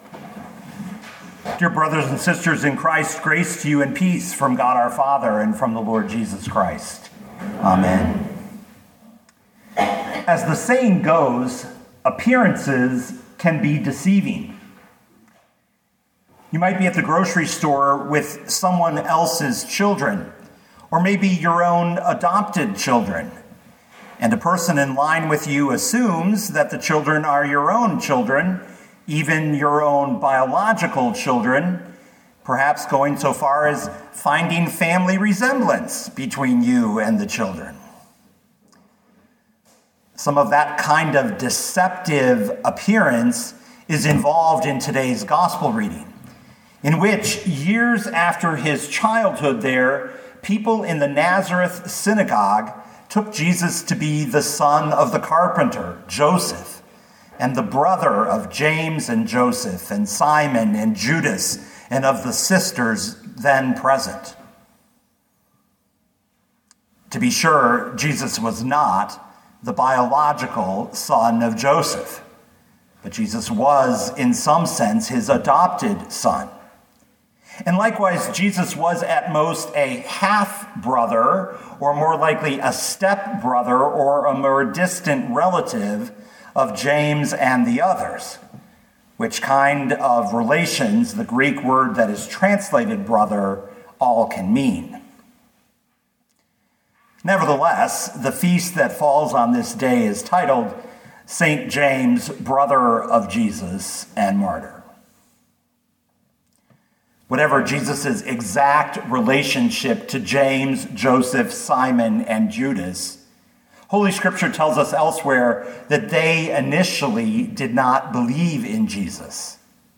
2022 Matthew 13:54-58 Listen to the sermon with the player below, or, download the audio.